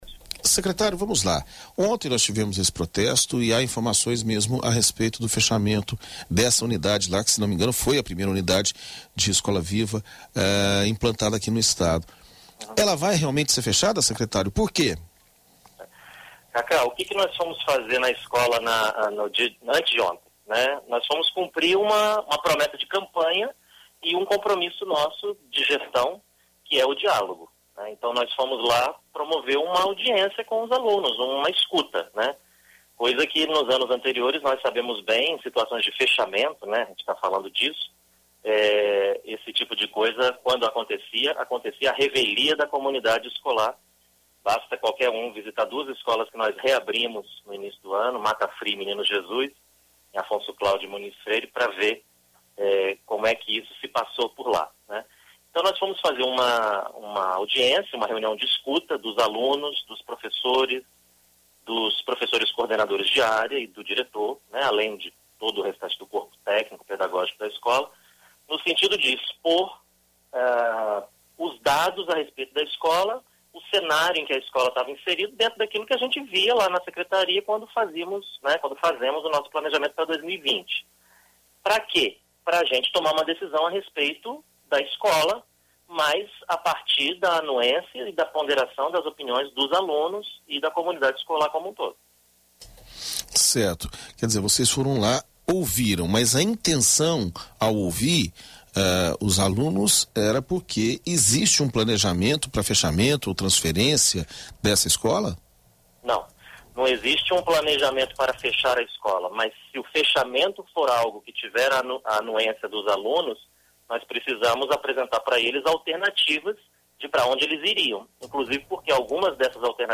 Na BandNews FM Espírito Santo, secretário de Educação se posiciona sobre protesto de alunos contra fechamento da Escola Viva
Em entrevista à BandNews FM Espírito Santo, o secretário de Estado da Educação, Vitor de Ângelo, explicou que representantes de Sedu foram até a escola para ouvir a comunidade. Ele ainda afirmou que caso a unidade seja realmente fechada, outra escola da região passará a ofertar a modalidade de tempo integral para os alunos.